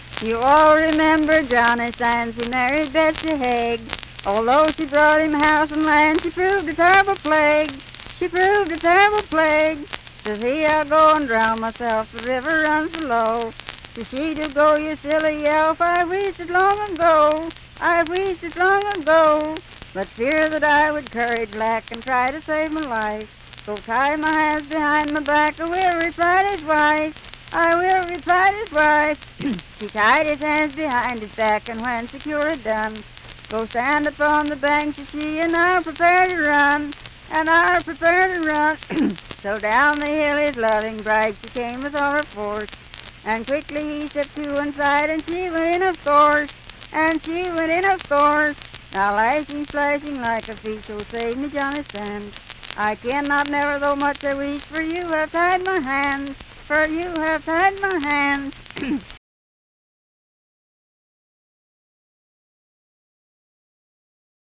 Unaccompanied vocal music
Performed in Daybrook, Monongalia County, WV.
Voice (sung)